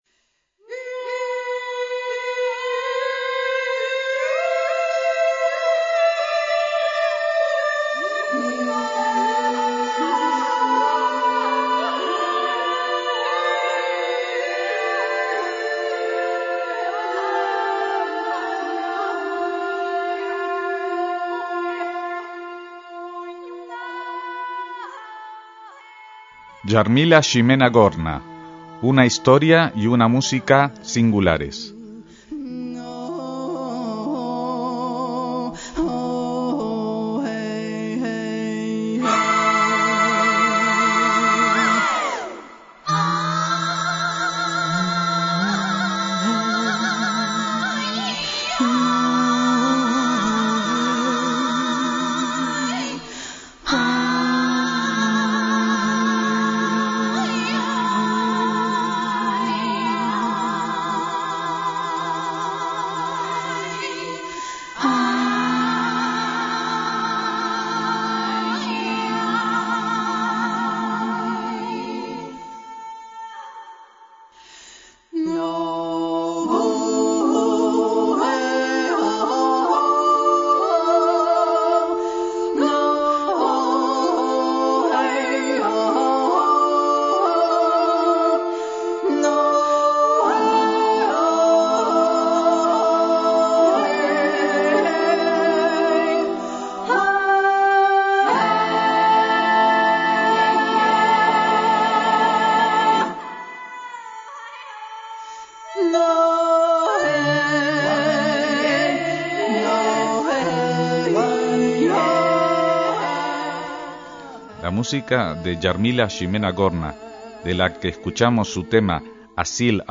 Su estilo es realmente indefinible, con reminiscencias de Kate Bush, Chick Corea, Ennio Morricone o el coro de las voces búlgaras.